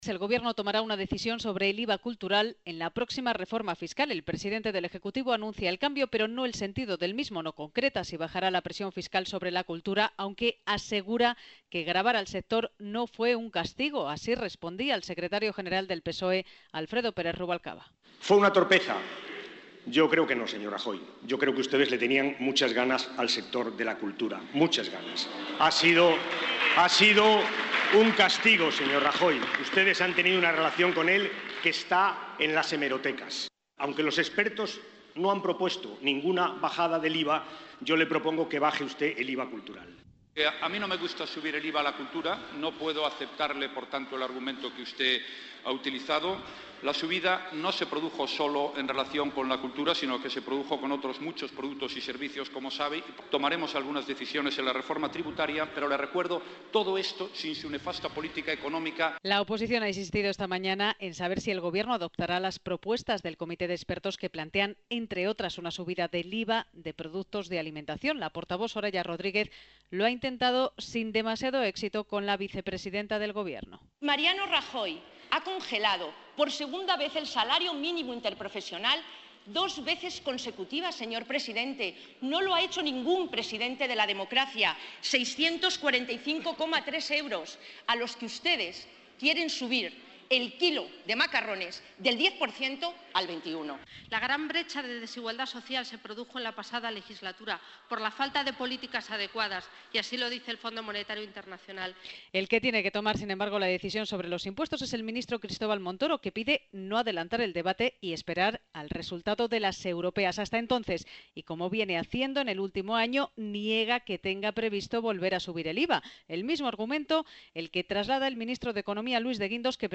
Así ha respondido el jefe del Gobierno en la sesión de control en el Congreso, preguntado por el secretario general del PSOE, Alfredo Pérez Rubalcaba, quien le ha propuesto que baje el IVA cultural, ante la "devastadora" situación que se ha producido en el sector al haber subido 13 puntos este tipo impositivo.